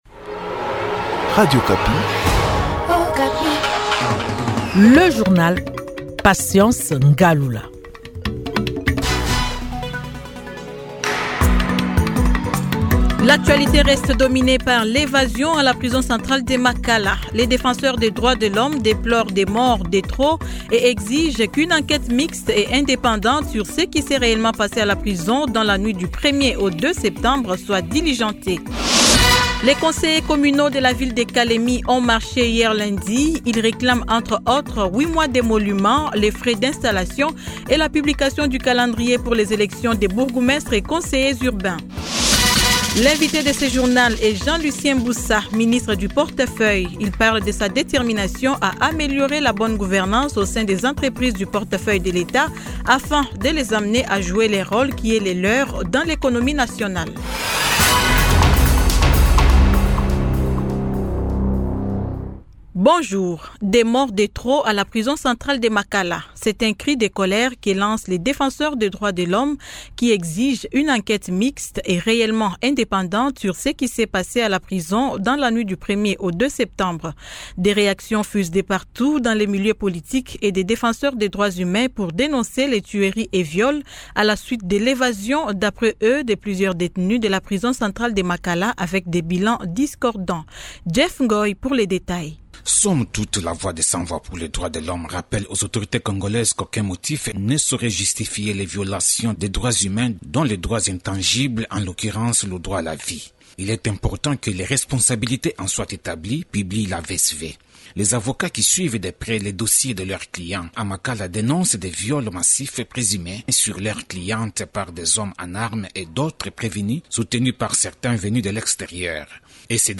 Journal 15H00
Kinshasa : Témoignage d’un voisin de la prison de Makala.